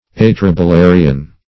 Atrabilarian \At`ra*bi*la"ri*an\, n.